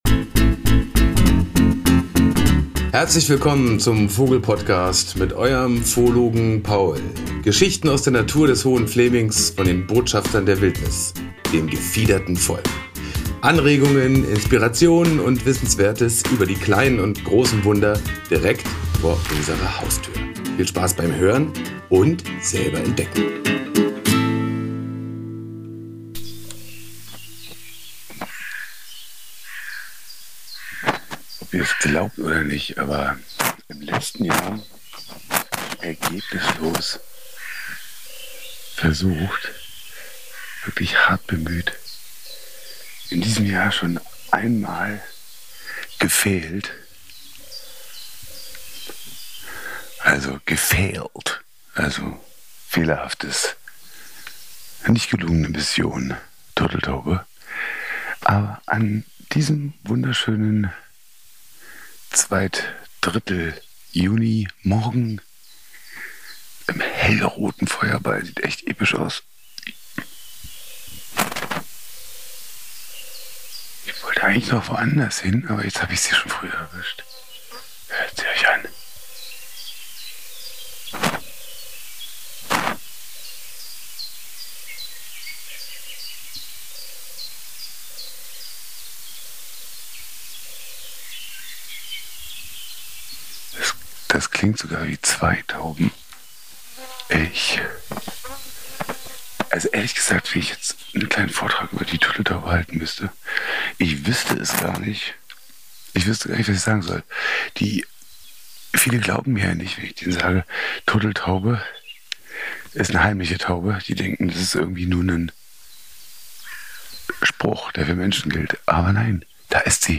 Beschreibung vor 5 Monaten (Empfehlung: Klangbildreise mit Kopfhörern genießen!)
Völlig unerwartet höre ich plötzlich das sanfte Gurren einer Turteltaube. Es ist eins meiner liebsten Naturgeräusche, die Sanftheit und das Zarte des Rufes ist mit meiner Technik schwer einzufangen.
Dafür ist der goldene Gesang vom Ortolan im zweiten Teil dieser Folge umso besser zu hören Und zwar gleich von drei Individuen, mit jeweils unterschiedlichen Motiven.
Ein weiterer akustischer Leckerbissen, etwas manisch, auf jeden Fall extrovertiert und glamourös, fast ein bisschen zu viel.